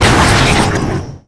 acannonaltfire01.wav